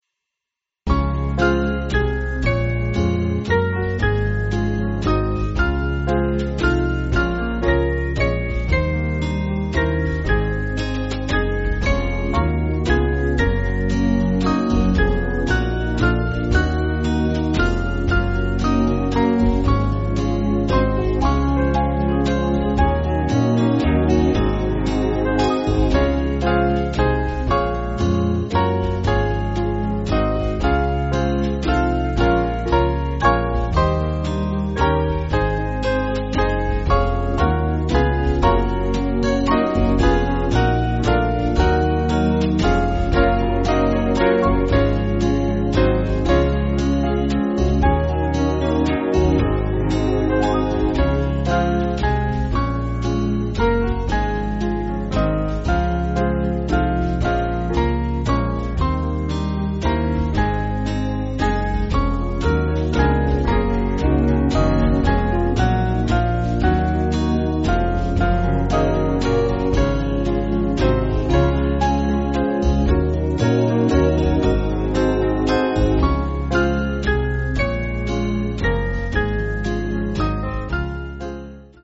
Small Band
(CM)   5/Db-D